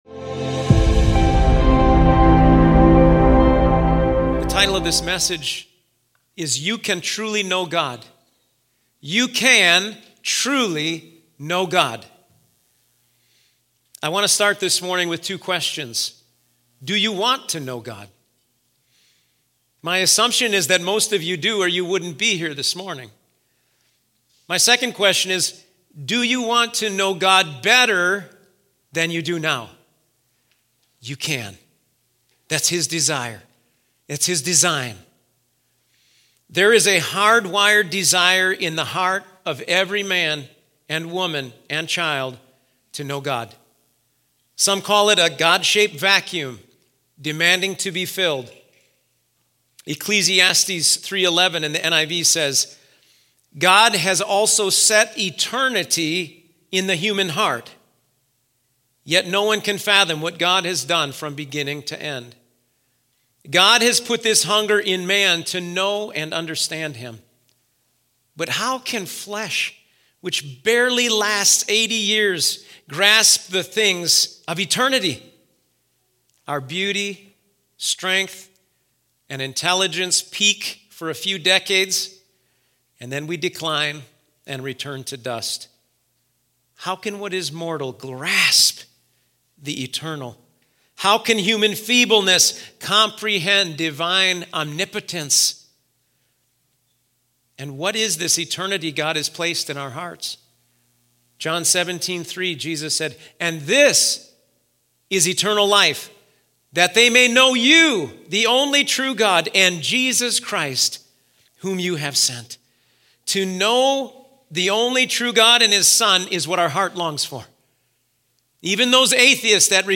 You Can Truly Know God | Auxano Church Sermons